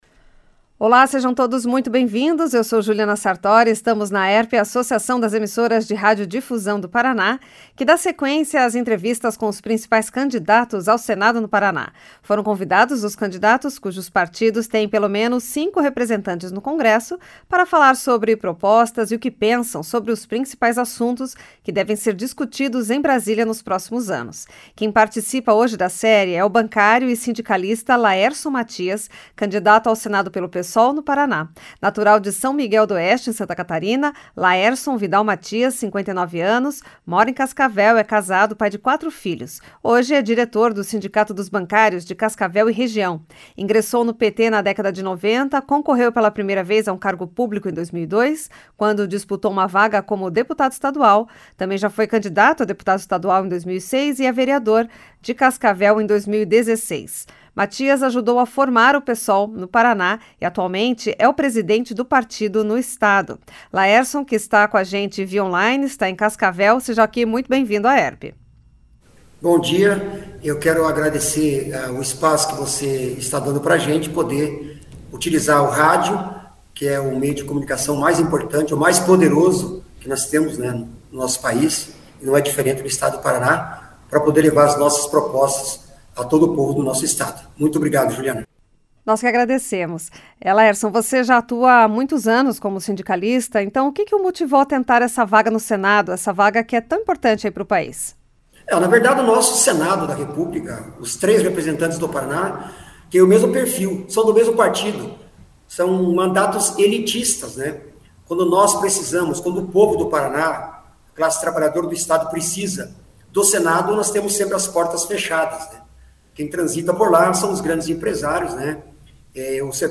Entrevista
Na entrevista realizada de forma remota